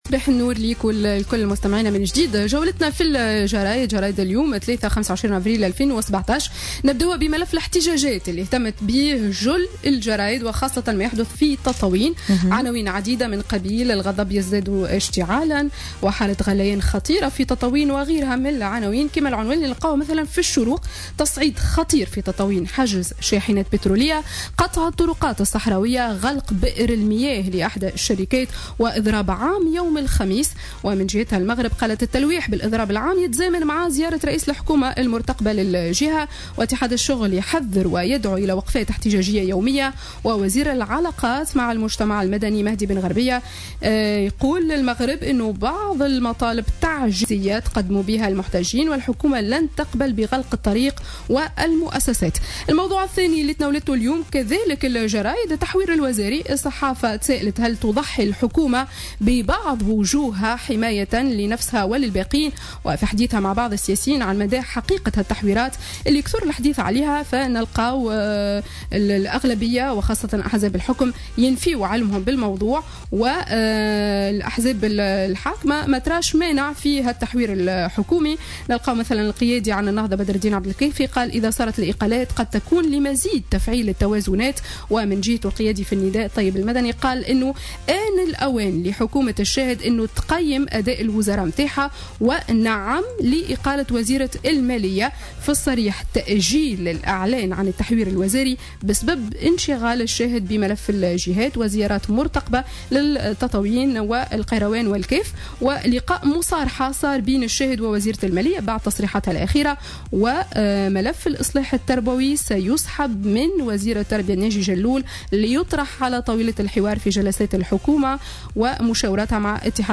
Revue de presse du mardi 25 Avril 2017